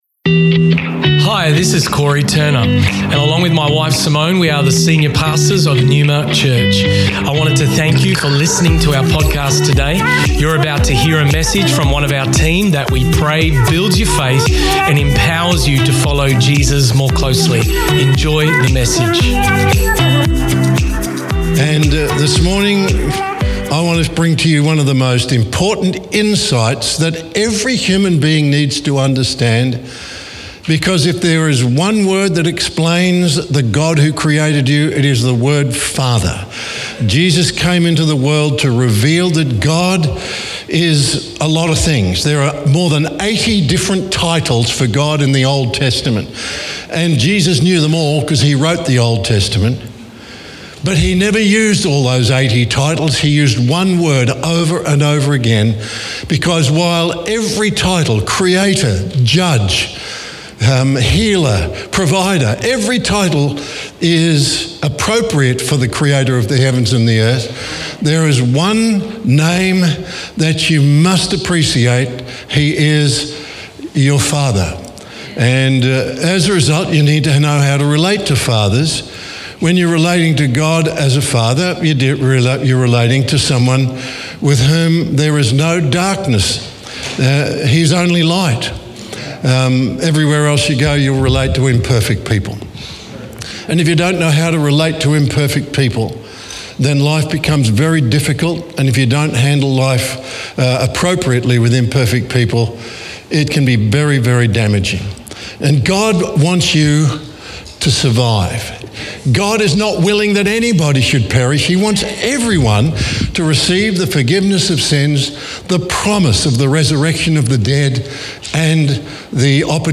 Neuma Church Melbourne South Originally Recorded at the 10am Service on Sunday 10th September 2023.&nbsp